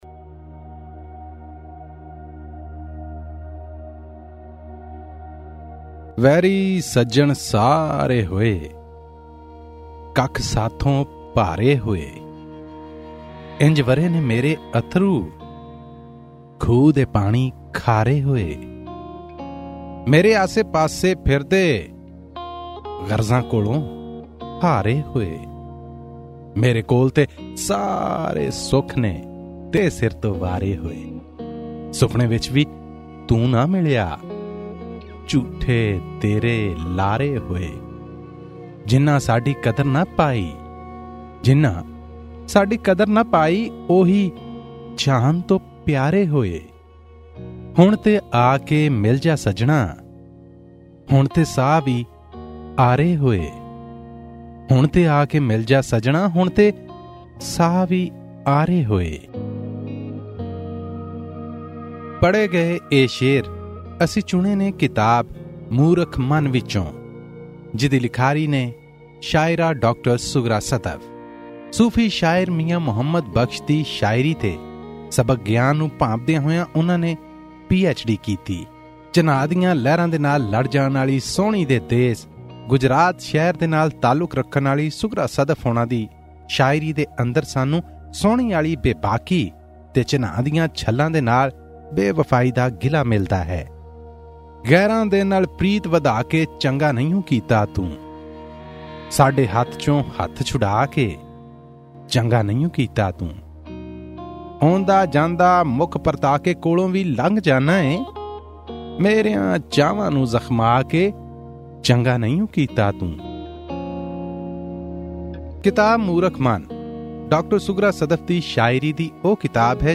Pakistani Punjabi poetry book review Source: Pixabay